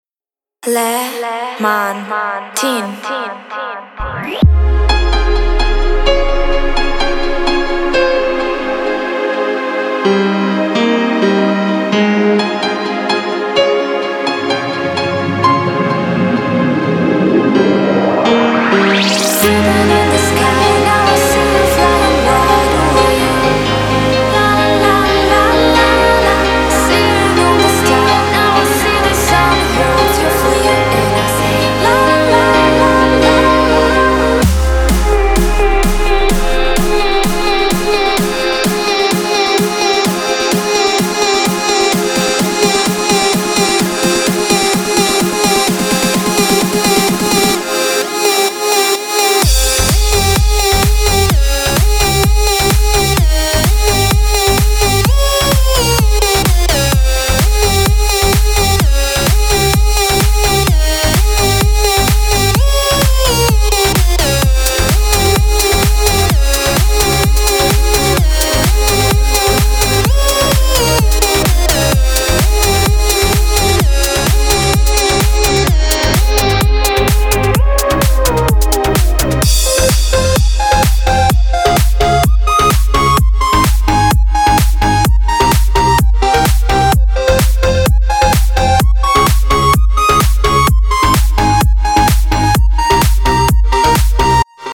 реворк